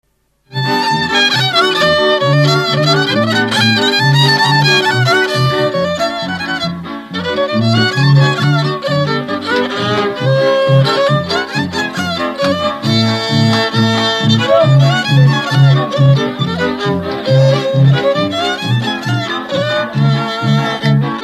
Dallampélda: Hangszeres felvétel
Erdély - Szolnok-Doboka vm. - Szék
hegedű
brácsa
bőgő
Műfaj: Csárdás
Stílus: 1.1. Ereszkedő kvintváltó pentaton dallamok